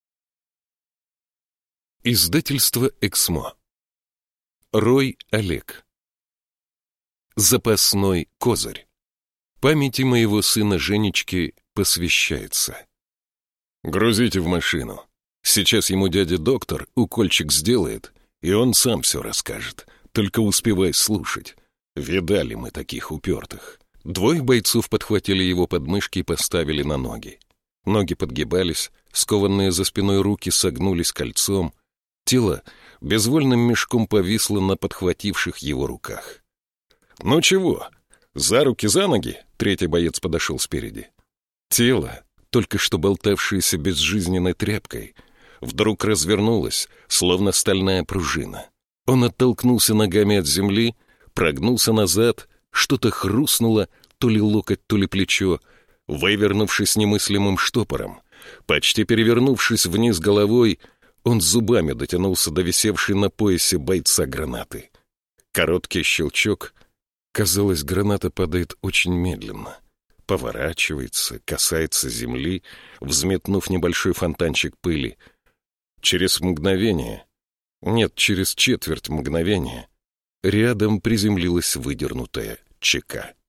Аудиокнига Запасной козырь | Библиотека аудиокниг